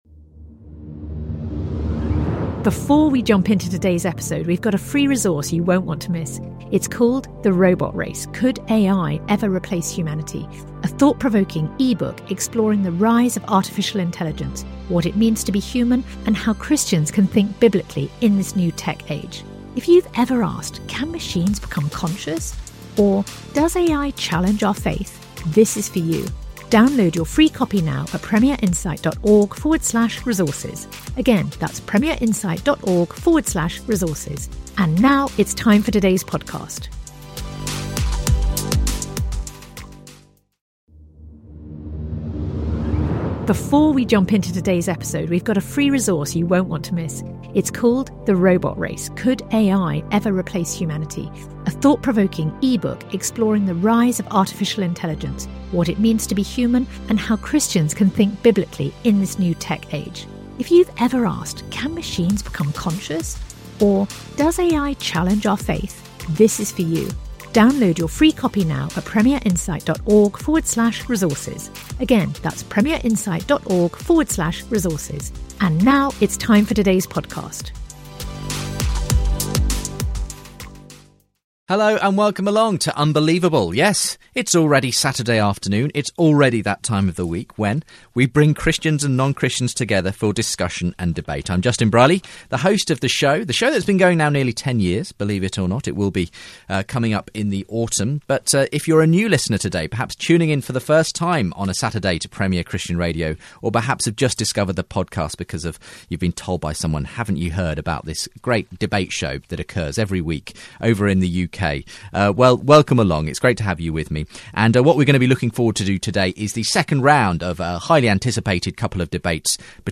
For more faith debates visit